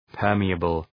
Προφορά
{‘pɜ:rmıəbəl}